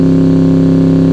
Engine
Fast_Low.wav